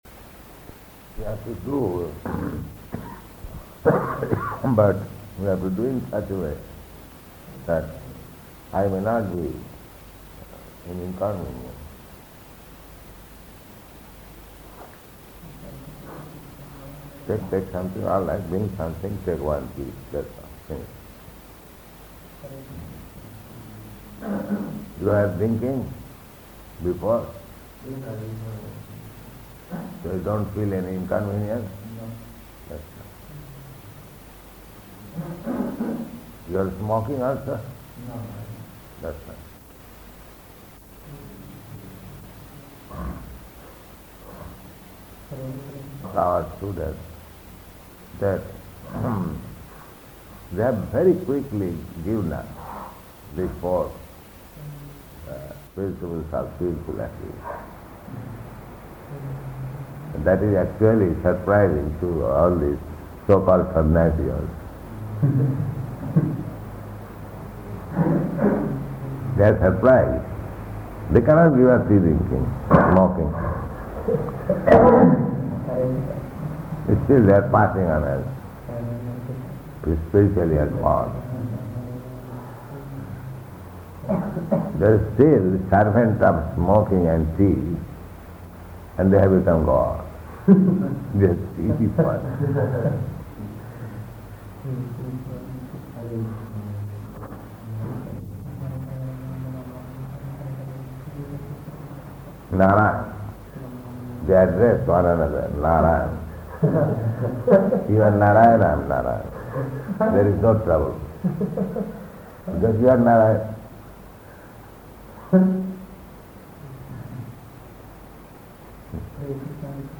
Room Conversation [Partially Recorded]
Location: Indore